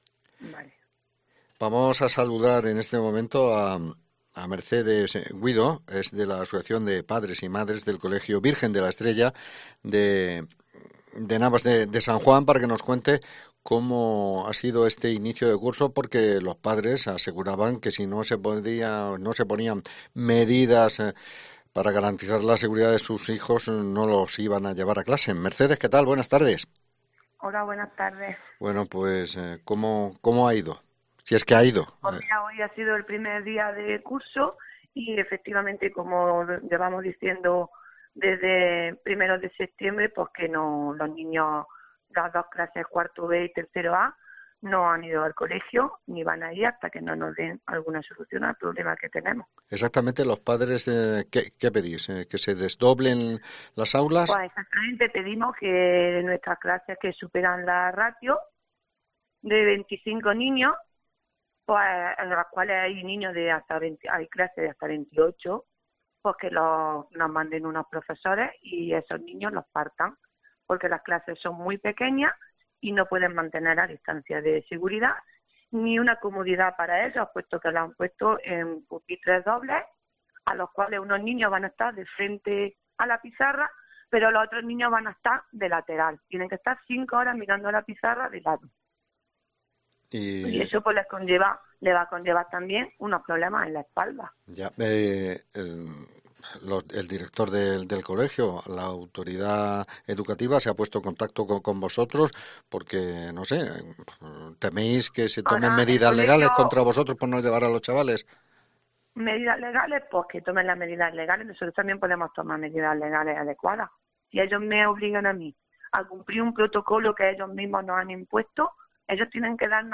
Sin embargo en Navas de San Juan unos 50 niños no han ido a calse porque sus padres denuncian que no se cumplen los protocolos anticovid. Hemos hablado con una de las madres